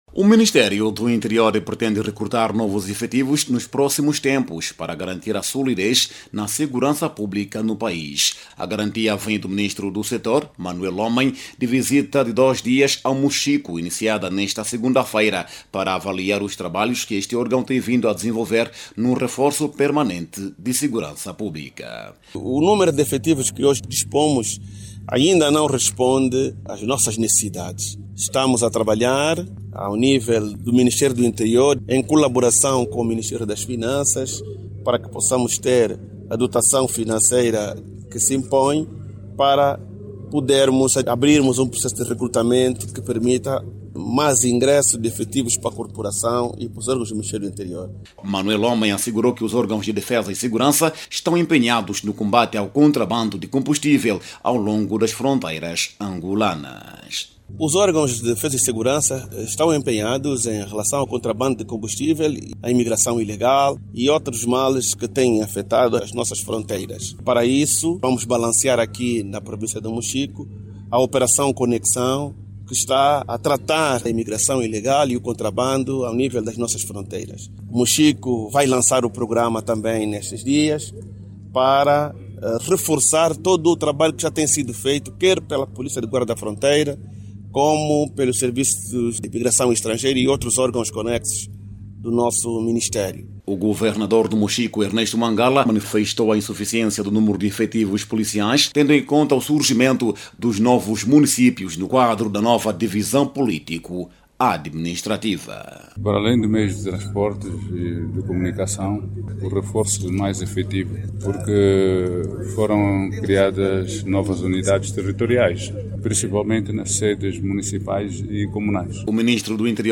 O Ministro do Interior fez estas declarações na província do Moxico, onde se encontra a trabalhar.